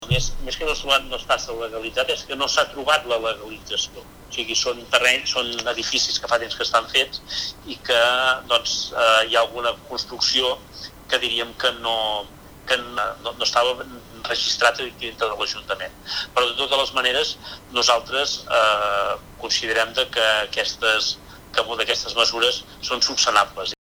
Així ho ha explicat en declaracions a Ràdio Capital.